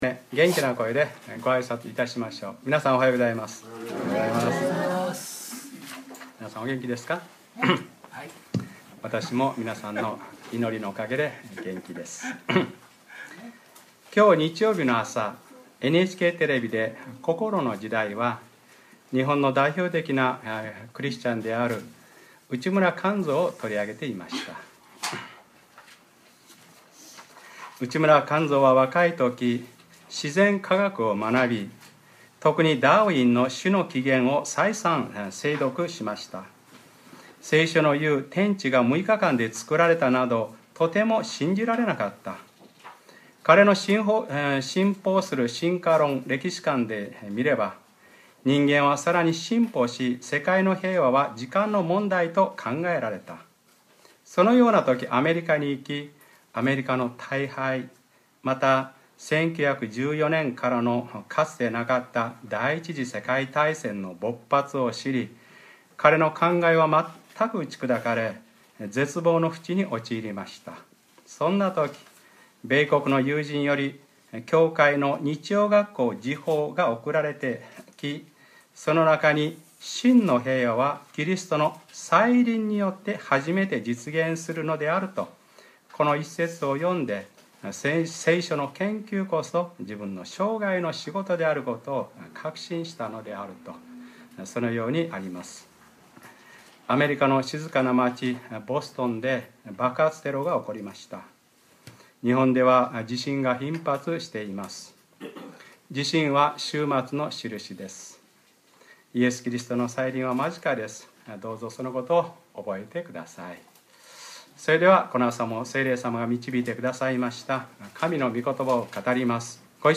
2013年4月21日(日）礼拝説教 『マラナ・タ（再臨と携挙）』